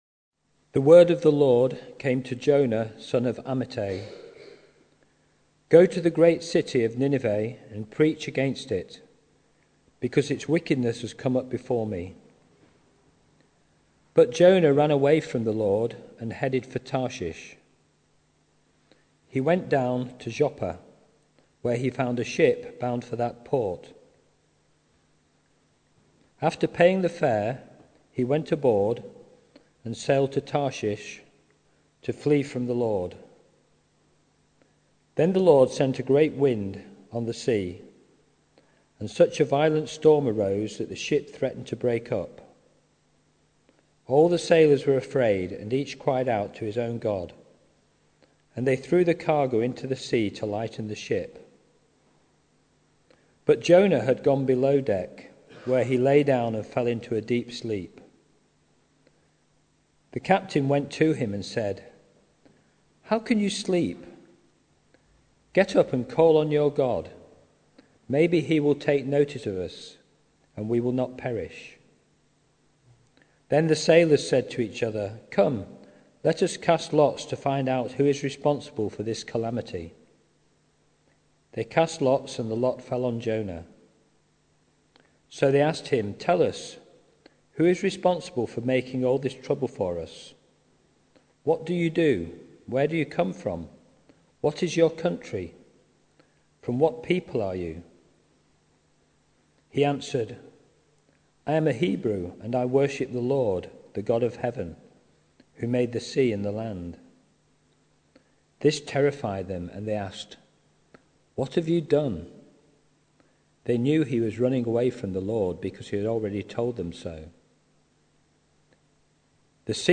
Jonah 1 Service Type: Sunday Evening Bible Text